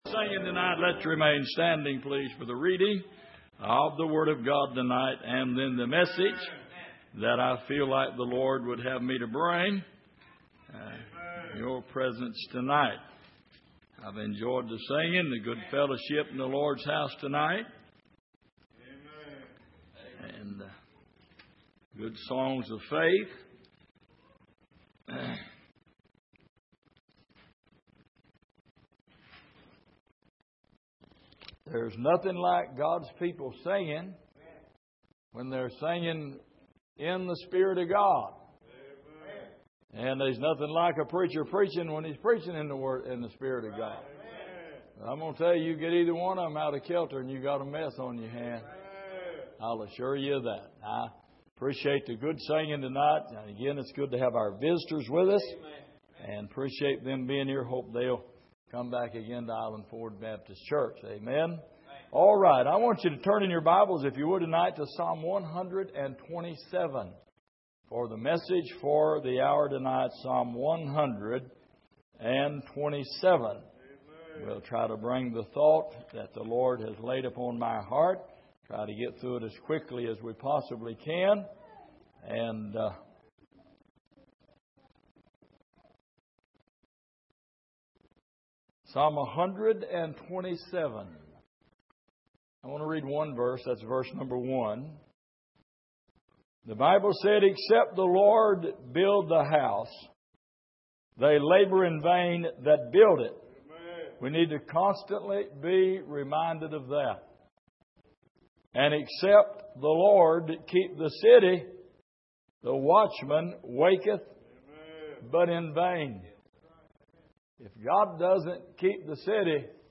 Passage: Psalm 127:1 Service: Sunday Evening